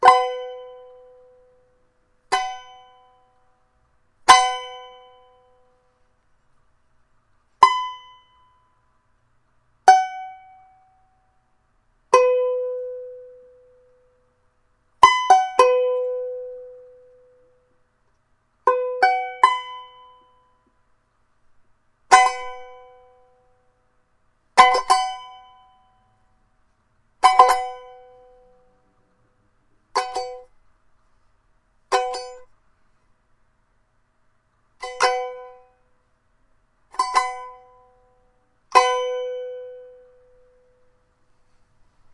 弦乐棒 " 弦乐棒9音阶条琴
描述：弦乐弹拨用蓝雪球录制，16bit
Tag: 俯仰 乱弹 strumstick 乐器 吉他 strumstick 拔毛 笔记 样本